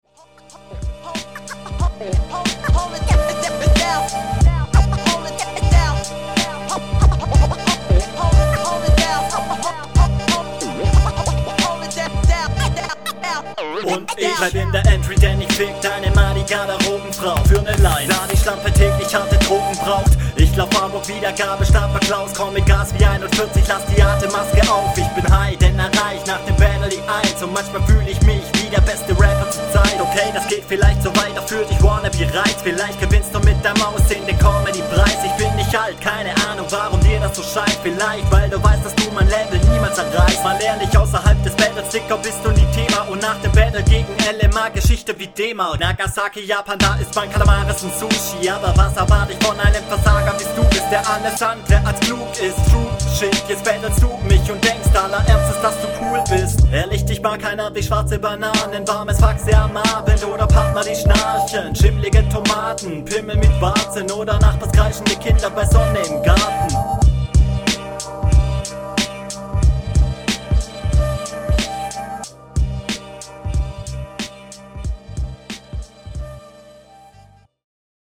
Dein Flow ist echt gut und dein Stimmeinsatz auch.
Wie erwartet kommst du fresher und fetter auf dem Beat.